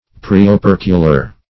Search Result for " preopercular" : The Collaborative International Dictionary of English v.0.48: Preopercular \Pre`o*per"cu*lar\, a. (Anat.) Situated in front of the operculum; pertaining to the preoperculum.